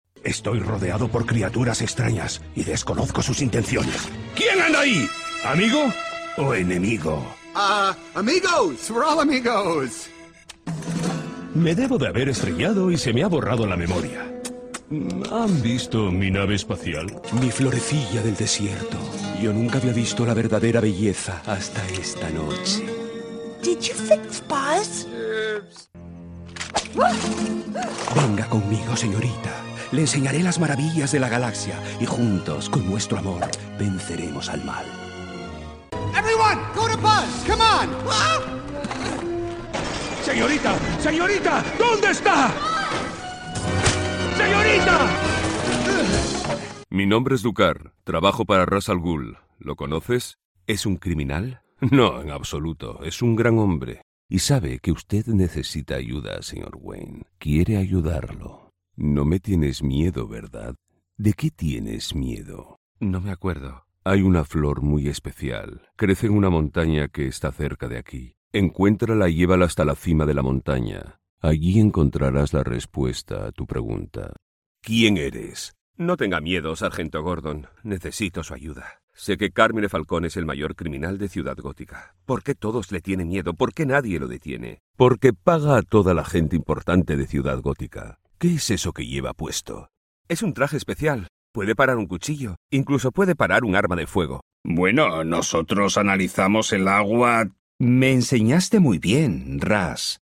Video Game Showreel
Male
Friendly
Smooth
Warm
Confident